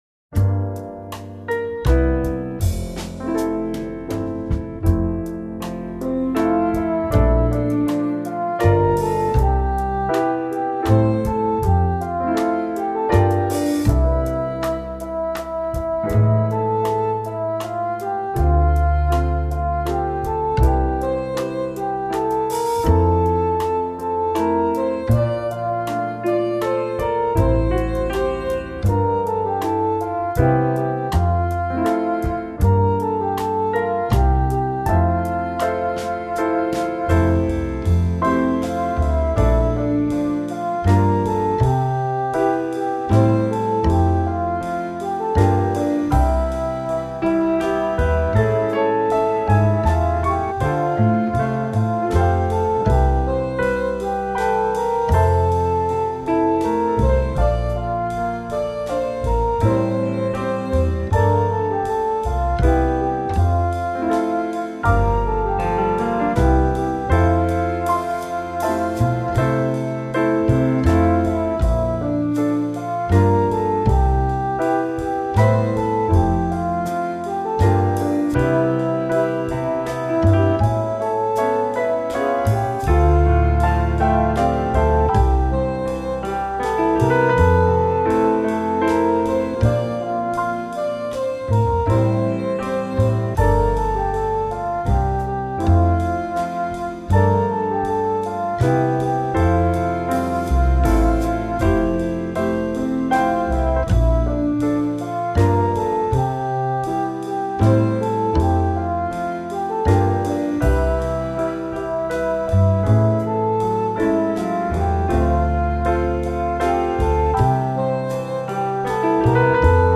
The rhythmic shifts in the last line work well.
My backing is approximate as I guessed the chords.